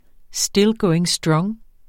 Udtale [ ˈsdel ˈgɔweŋ ˈsdɹʌŋ ]